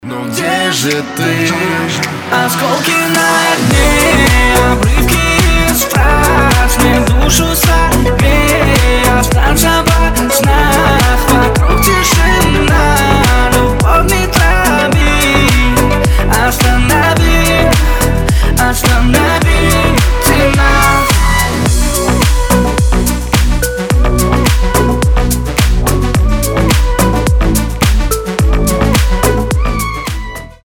• Качество: 320, Stereo
восточные мотивы
dance